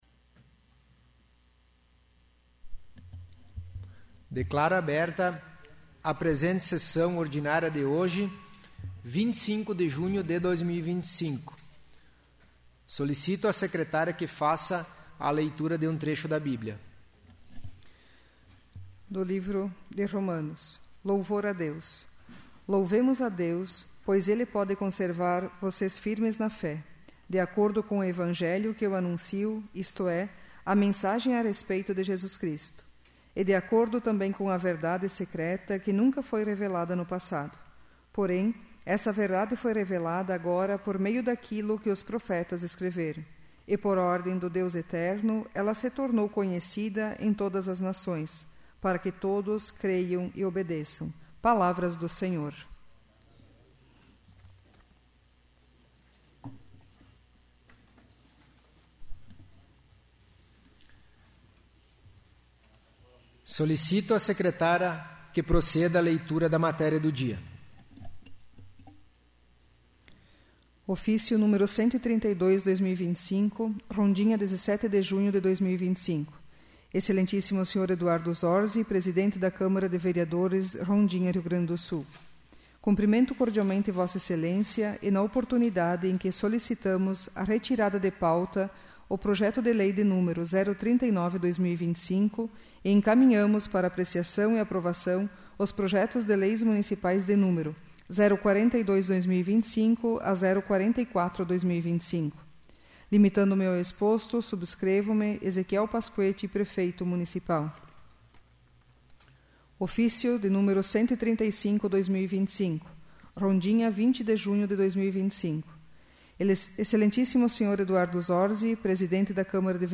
'áudio da sessão do dia 08/04/2026'